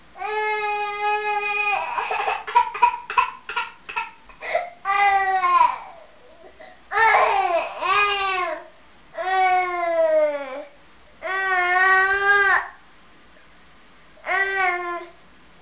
Baby Crying Sound Effect
Download a high-quality baby crying sound effect.
baby-crying.mp3